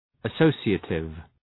associative.mp3